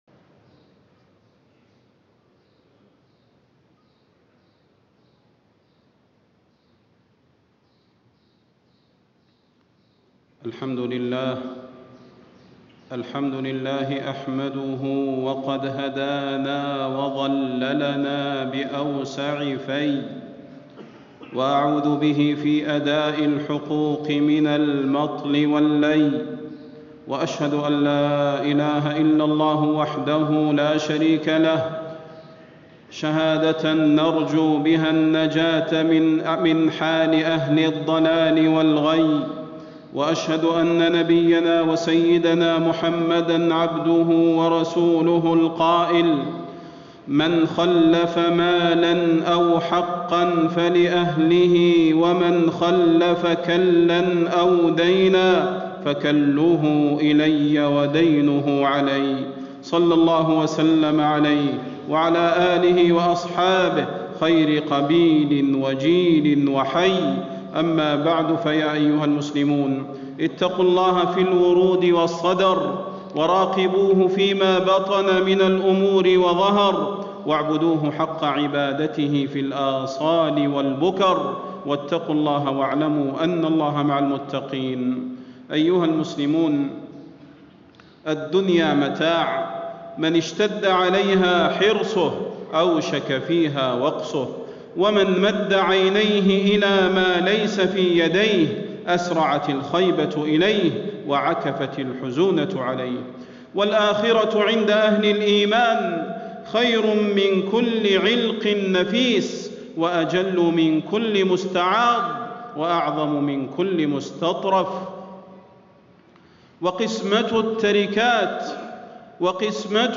خطبة الجمعة 8 رجب 1437هـ > خطب الحرم النبوي عام 1437 🕌 > خطب الحرم النبوي 🕌 > المزيد - تلاوات الحرمين